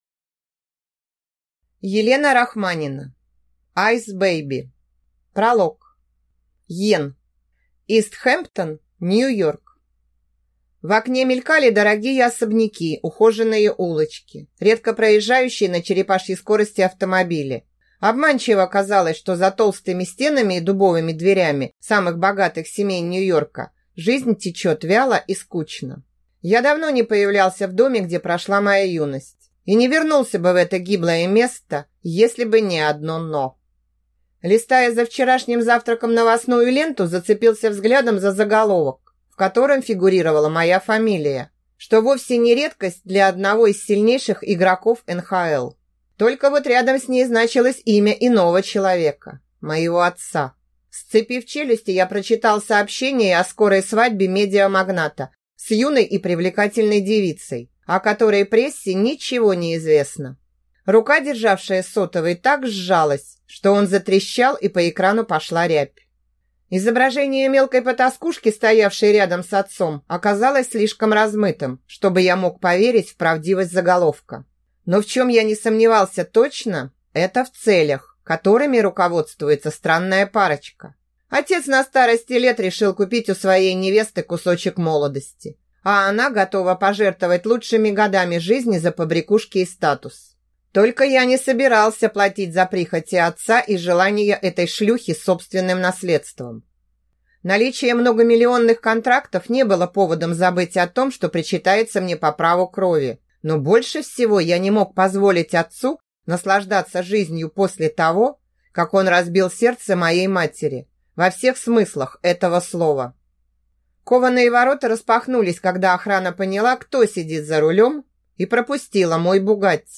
Аудиокнига Айс бэйби | Библиотека аудиокниг
Прослушать и бесплатно скачать фрагмент аудиокниги